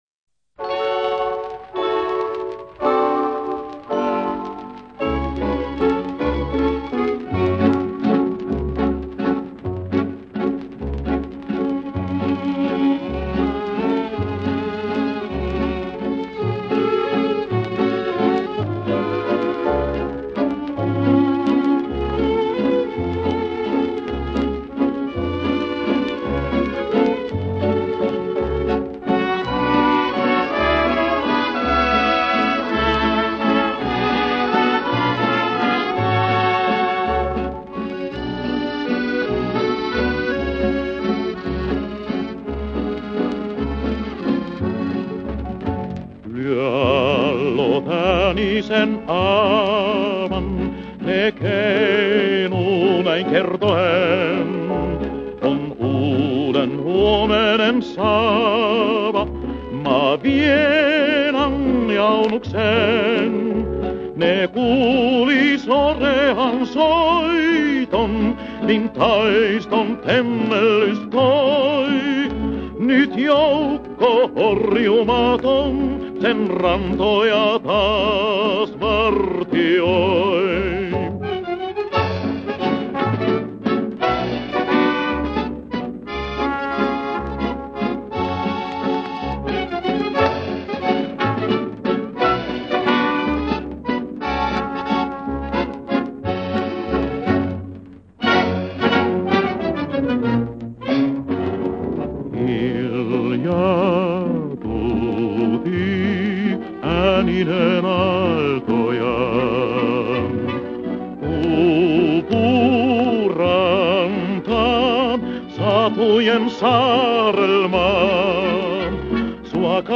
In Antwort auf den internationalen klassischen Walzer